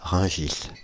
Rungis (French pronunciation: [ʁœ̃ʒis]
Fr-Paris--Rungis.ogg.mp3